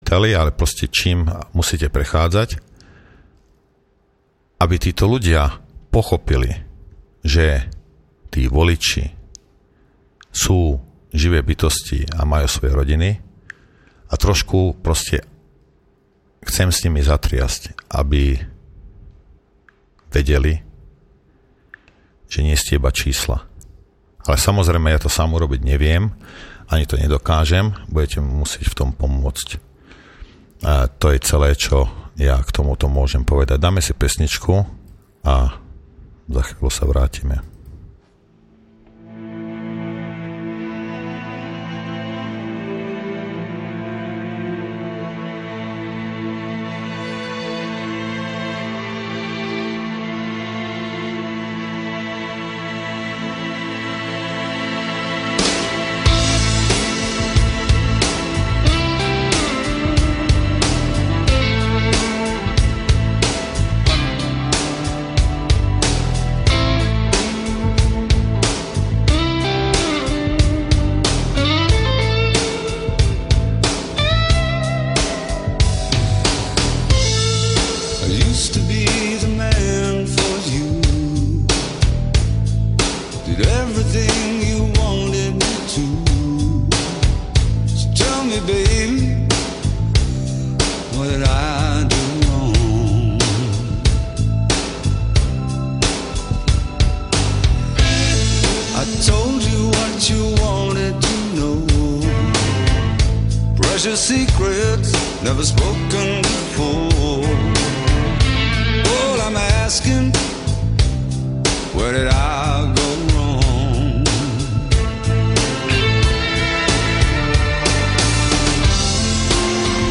Téma - Kauza Gorila .... súhrn, vyšetrovanie, názory politikov, prognózy .... diskusia s poslucháčmi.